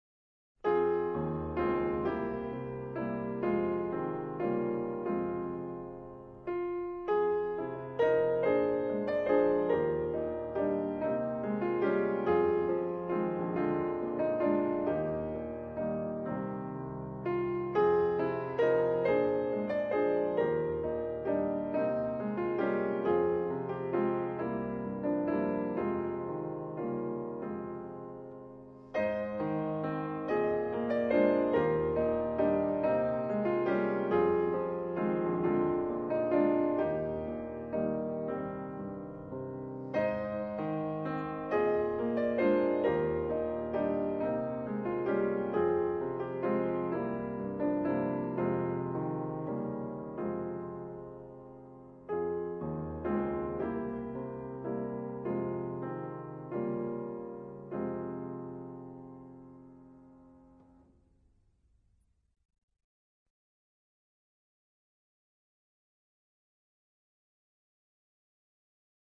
0218-钢琴名曲绿袖子.mp3